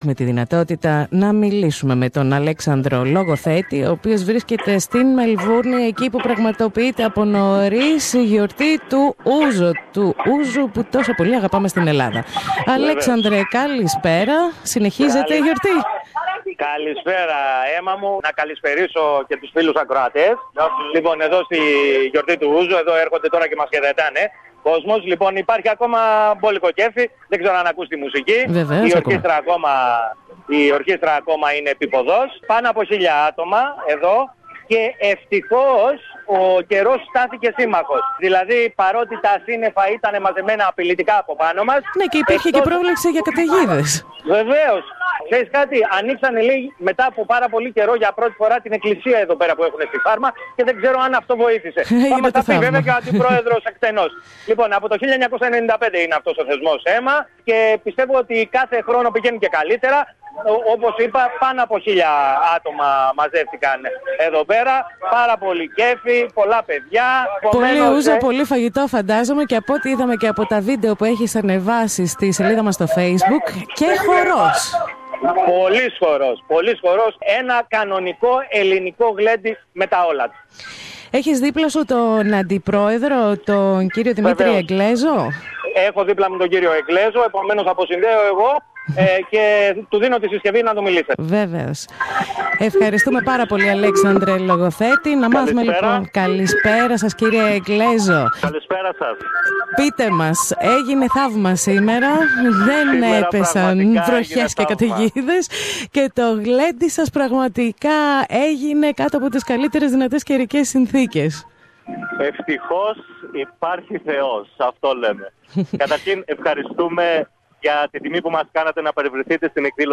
Στην μεγαλύτερη γιορτή Ούζου της Μελβούρνης, βρέθηκε το Ελληνικό πρόγραμμα της ραδιοφωνίας SBS